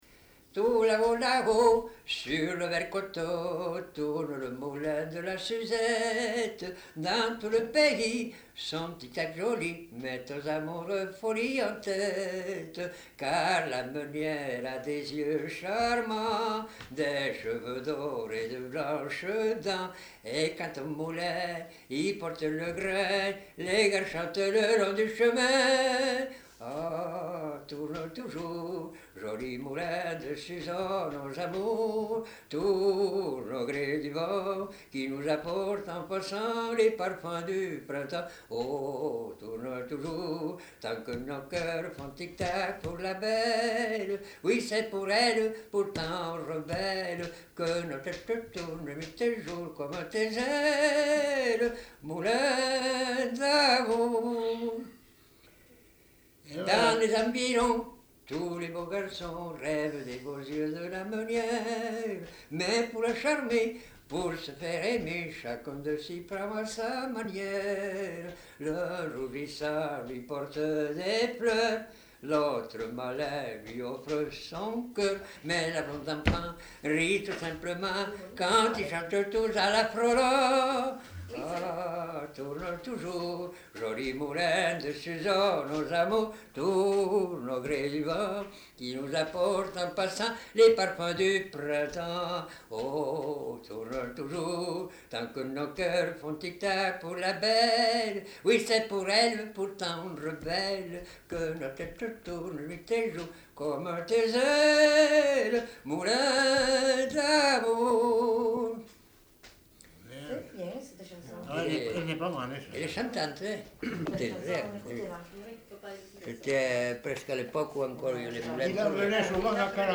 Aire culturelle : Lauragais
Lieu : Auriac-sur-Vendinelle
Genre : chant
Effectif : 1
Type de voix : voix d'homme
Production du son : chanté